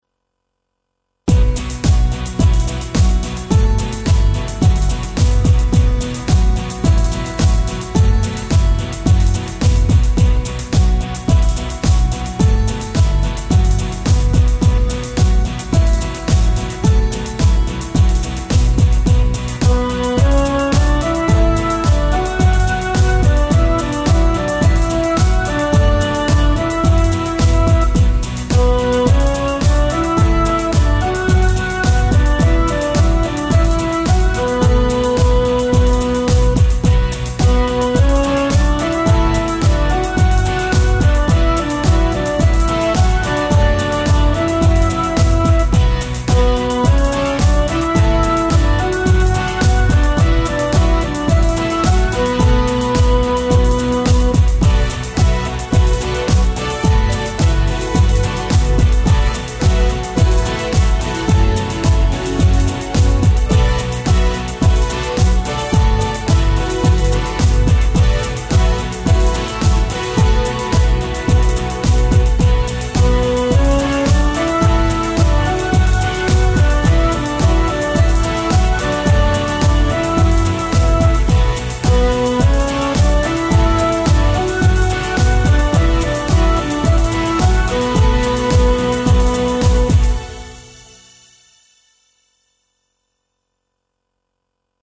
最近は、ドラムパートから作って、次いでベース、ギター、キーボードという順番に伴奏を作っていって、最後にメロディを付けていく感じ。
今回は１６トラック全部使えた。
今回は短調の曲。
あと、なんというか、本当はＢＰＭ２００以上の曲にしようとしていたんだけど、いつの間にか１１０くらいの曲になってしまった。